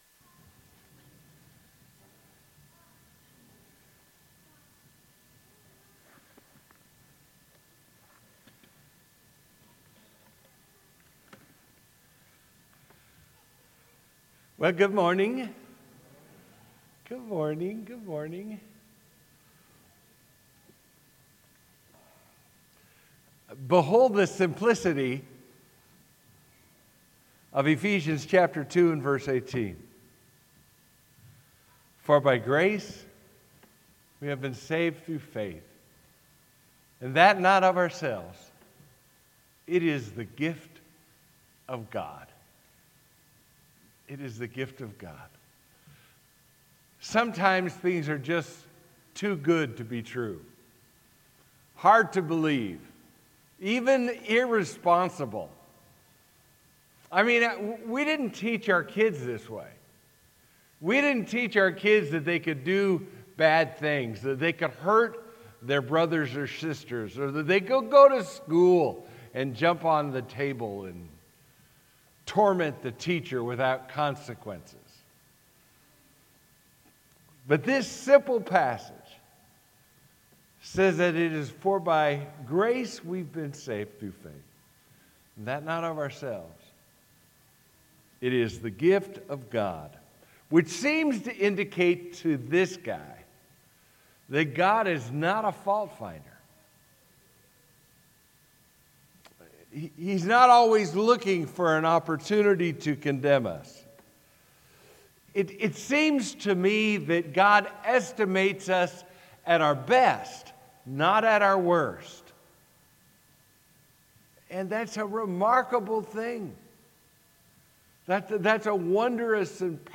Sermon: “Law and Grace”
sermon-january-8th-2023.mp3